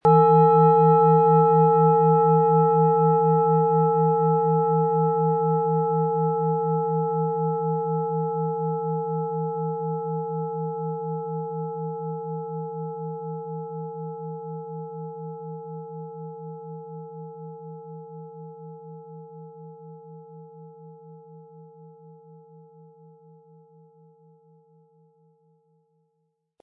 • Mittlerer Ton: Alphawelle
Sie möchten den schönen Klang dieser Schale hören? Spielen Sie bitte den Originalklang im Sound-Player - Jetzt reinhören ab.
PlanetentöneBiorythmus Seele & Alphawelle
MaterialBronze